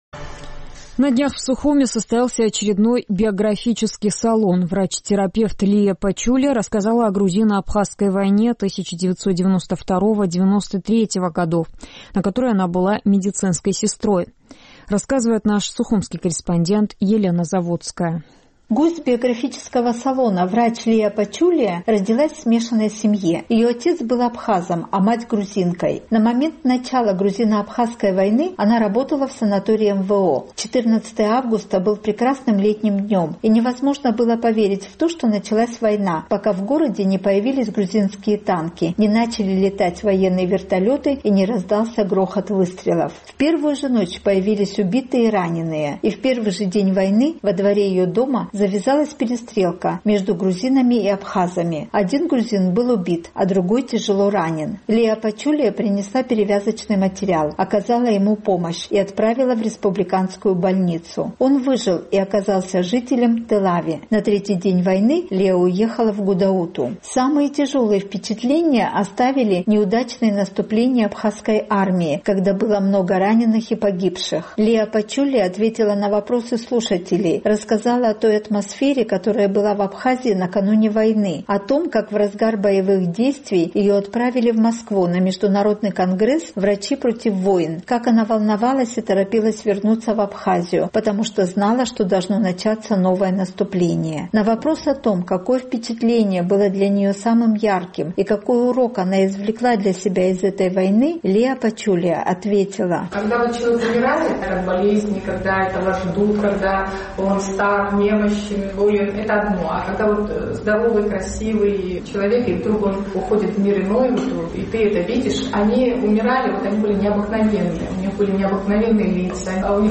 Сегодня в Сухуме состоялся очередной «Биографический салон».
Во время встреч ведется аудиозапись и видеосъемка.